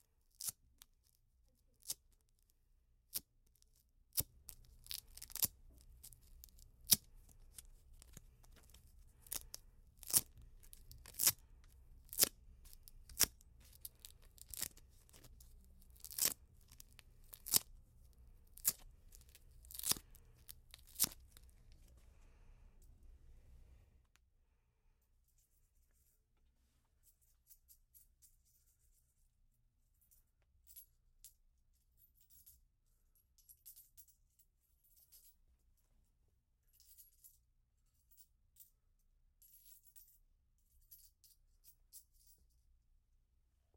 粘扣带
描述：魔术贴声音
Tag: 粘扣带 魔术贴声音 SOM-DE-魔术贴 安汉比 - 莫伦比